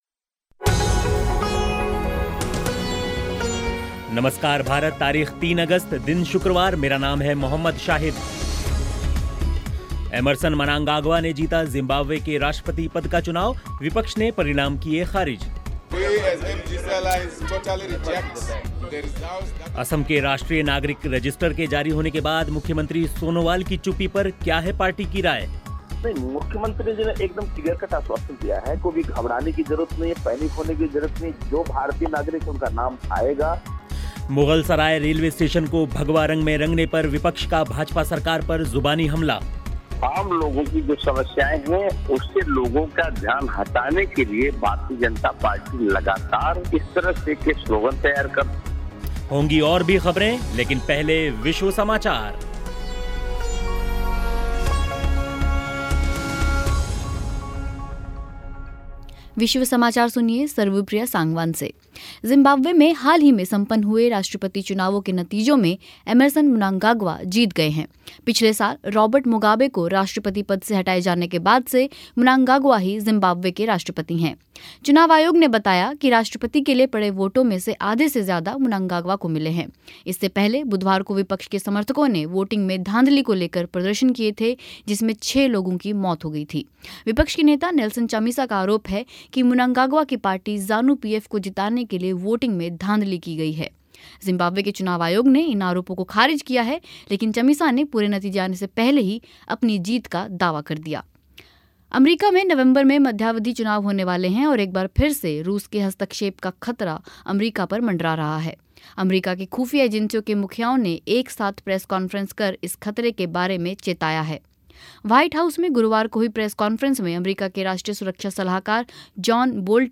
मुग़लसराय रेलवे स्टेशन को भगवा रंग में रंगा जा रहा है. अन्य ख़बरों के अलावा विश्व समाचार भी होंगे इस बुलेटिन में.